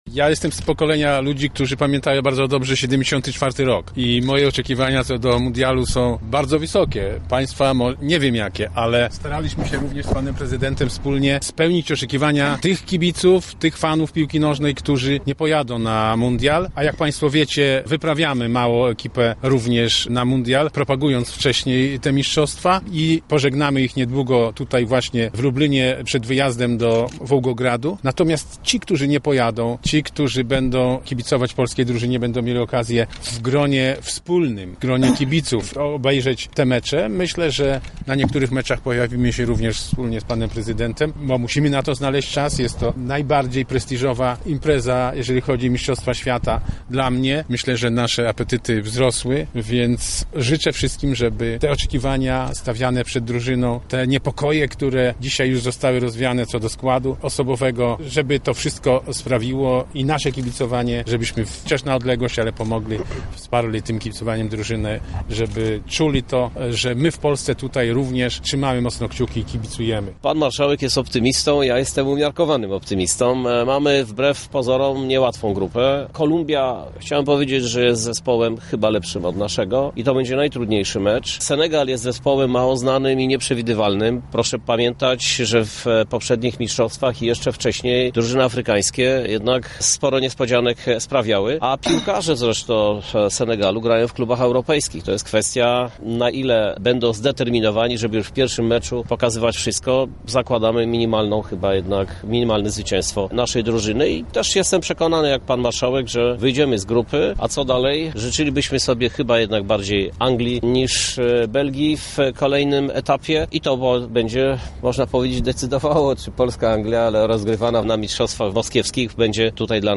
Strefę Kibica zaprezentowali marszałek województwa Sławomir Sosnowski oraz prezydent Krzysztof Żuk: